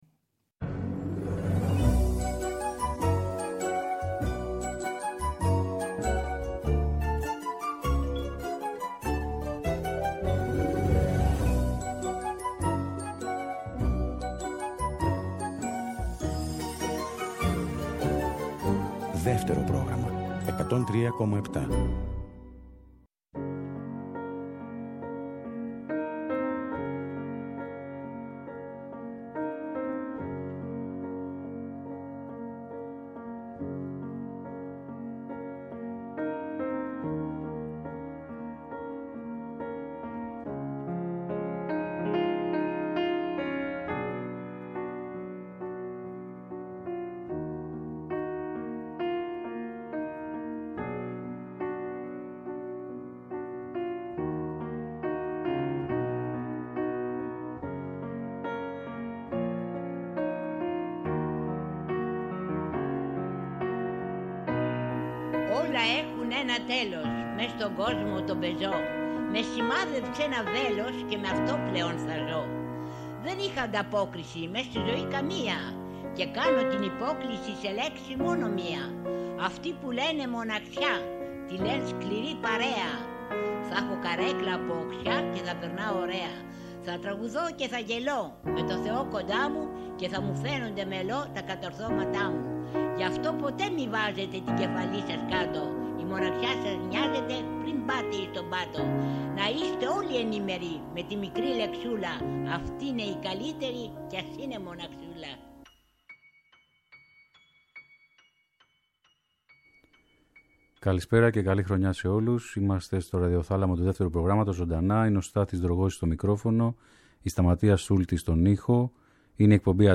Και στο τέλος της εκπομπής θα ακουστεί μια διασκευή με πιάνο και φωνή ειδικά για τους ακροατές του Δεύτερου.